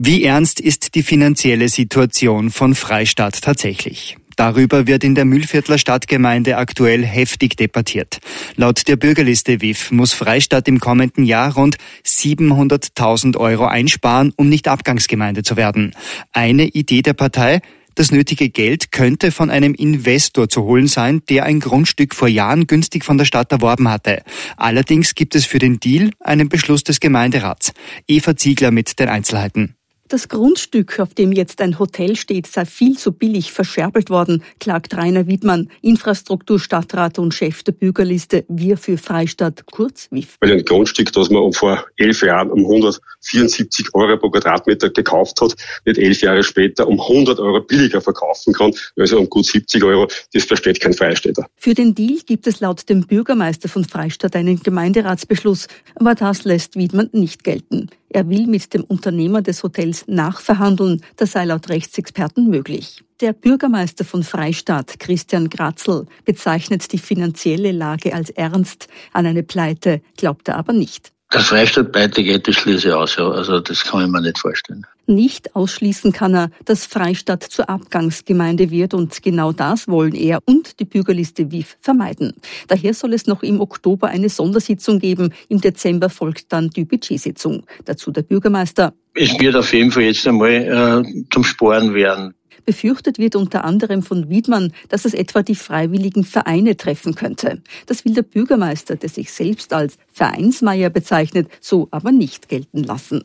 Mit Interviews von WIFF Infrastruktur-Stadtrat Rainer Widmann und Bürgermeister Christian Gratzl